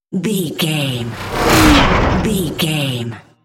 Sci fi whoosh electronic flashback
Sound Effects
Atonal
futuristic
intense